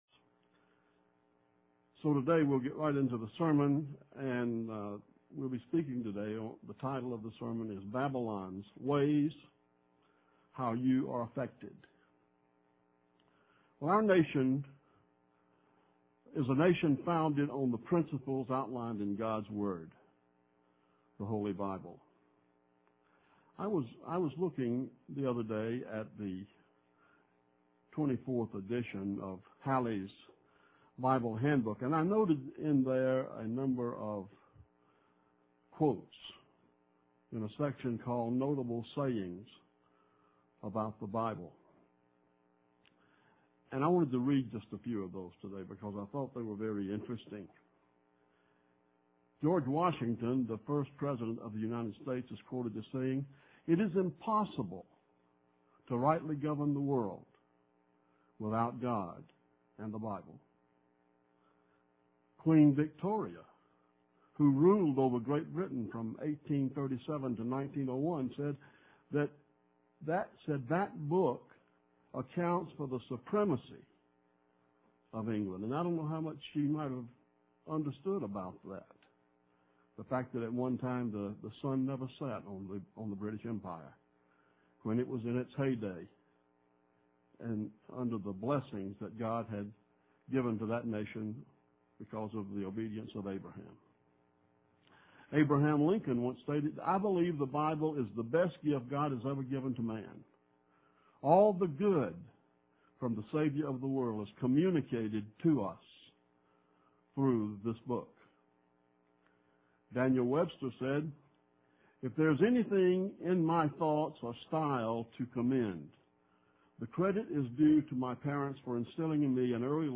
Sermons – Page 229 – Church of the Eternal God